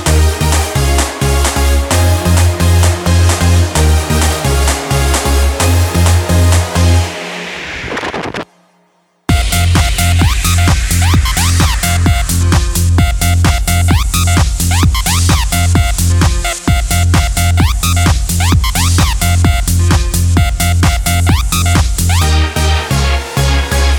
no Backing Vocals R'n'B / Hip Hop 4:23 Buy £1.50